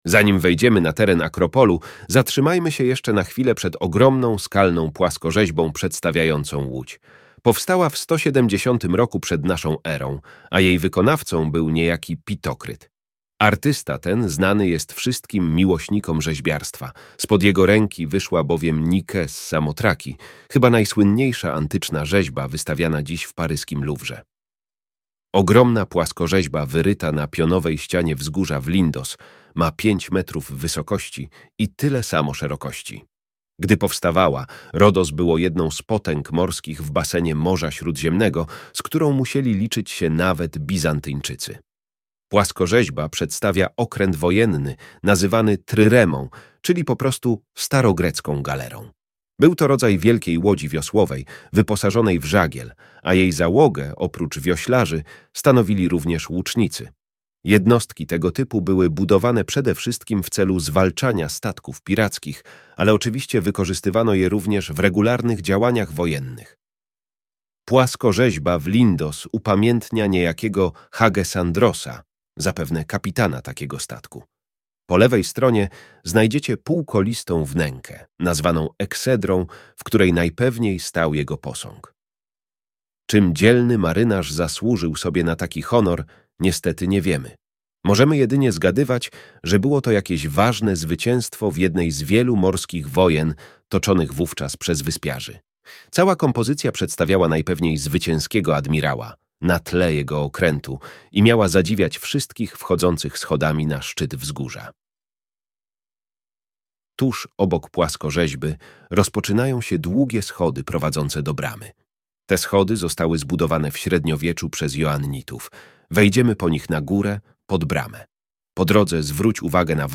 Audioprzewodnik po Lindos